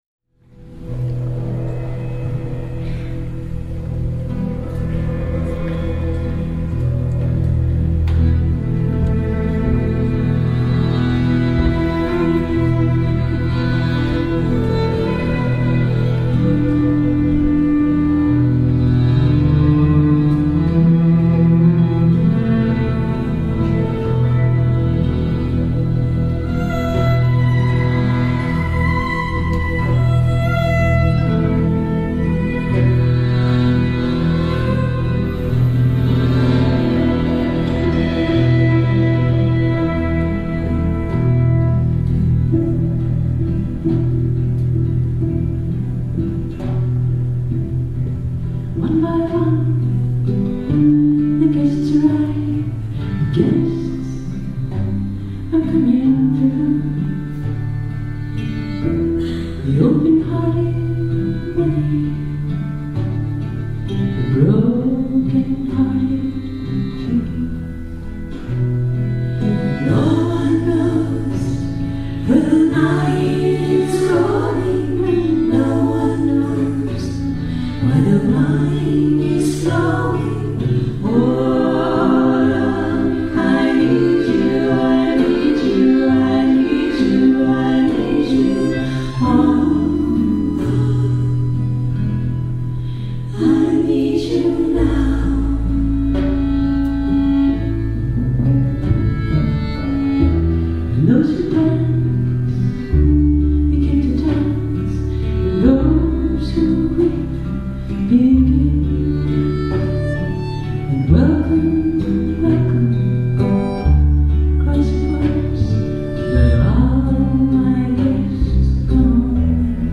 И это не то, это ливе а надо не ливе